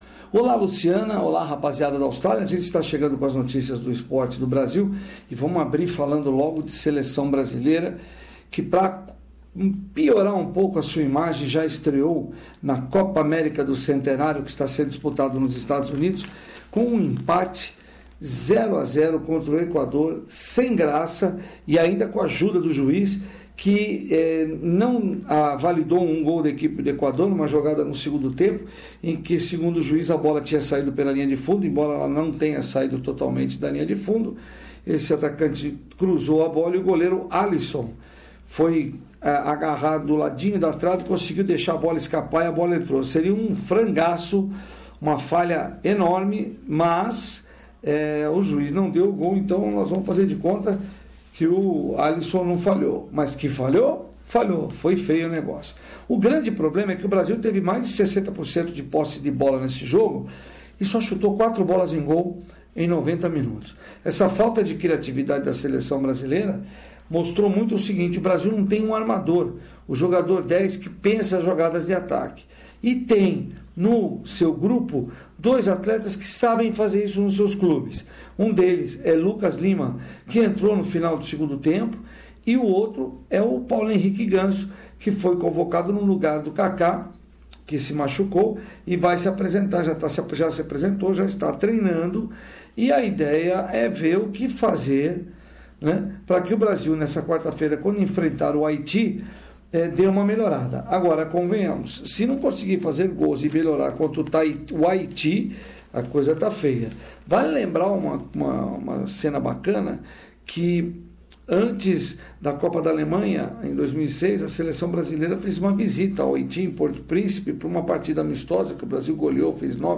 Sports news bulletin about Brazils performance, fans who turn violent and Brazilian championship results.